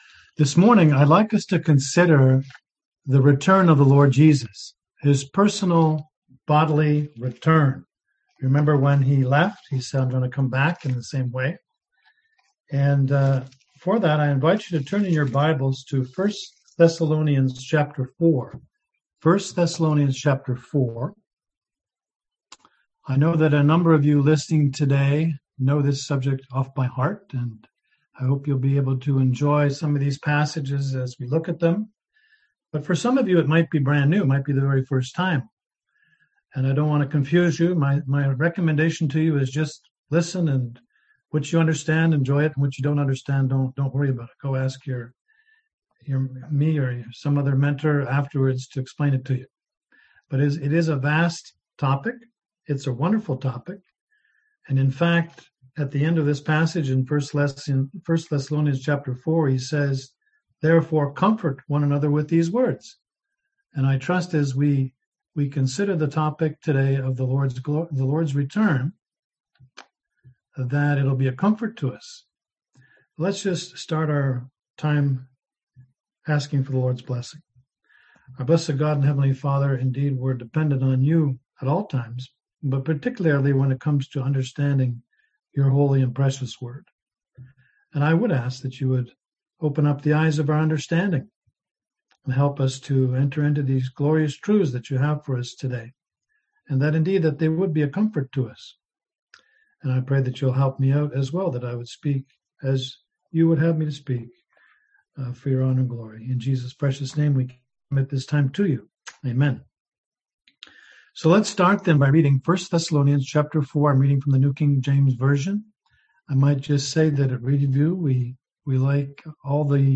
Passage: 1 Thess 4 Service Type: Sunday AM Topics: End Times , Lord's Return